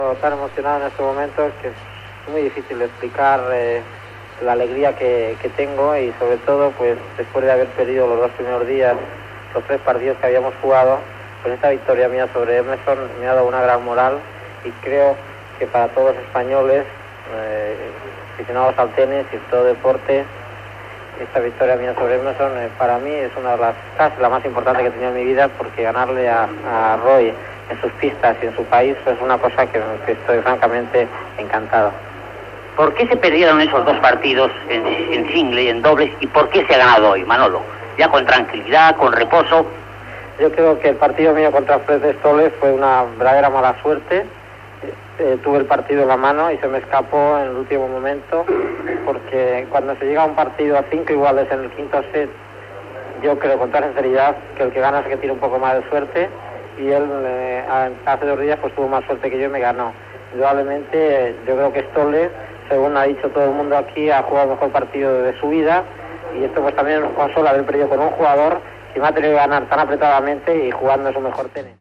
Entrevista al tennista Manolo Santana després del partit.